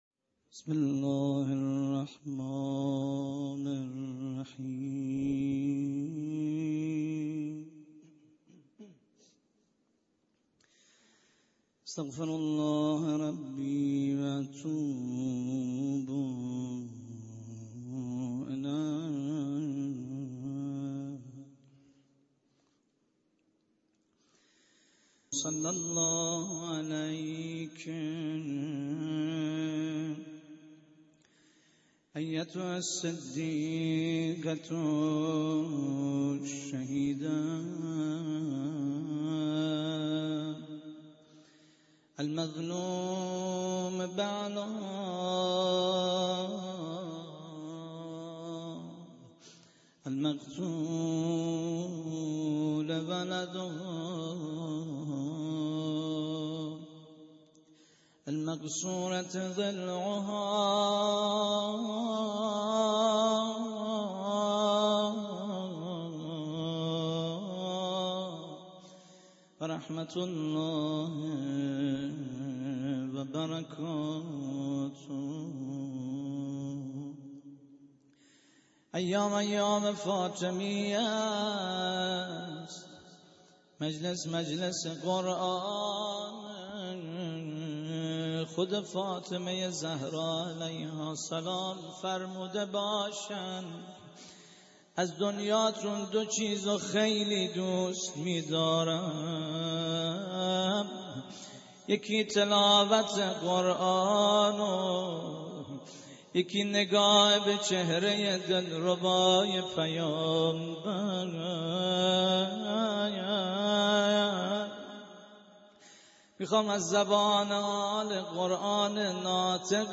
روضه ی حضرت فاطمه زهرا (سلام الله علیها)